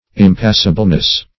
\Im*pas"si*ble*ness\, n. Impassibility.